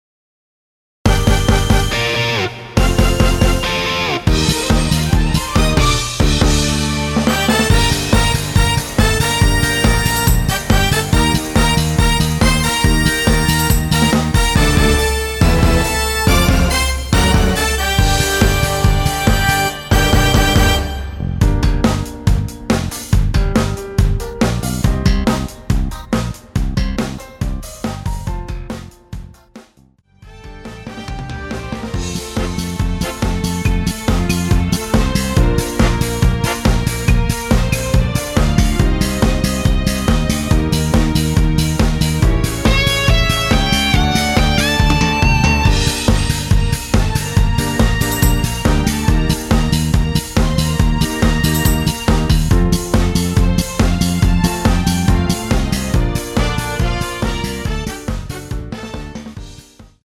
Gm
앞부분30초, 뒷부분30초씩 편집해서 올려 드리고 있습니다.